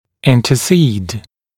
[ˌɪntə’siːd][ˌинтэ’си:д]вступаться, заступаться